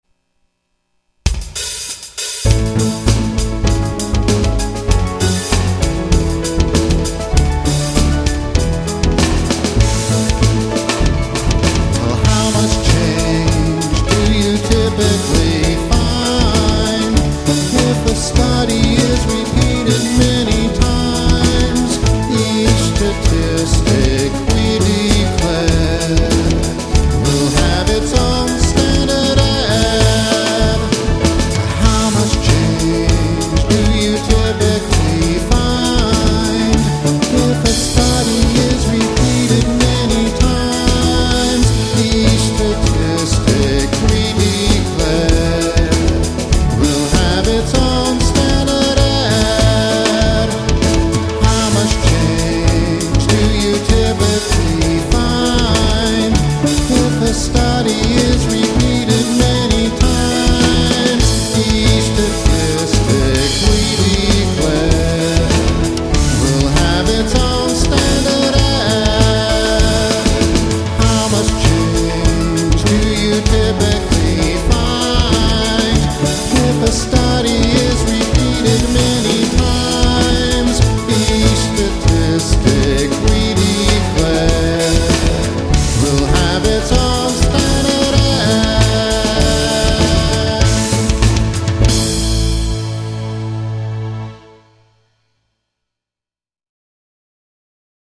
This is a page of stat music.